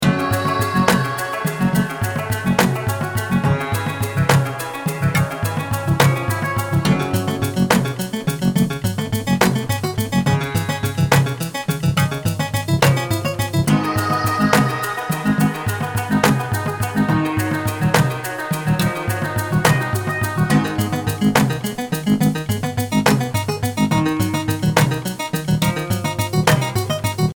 a sort of a fast-paced overworld to this one's battle.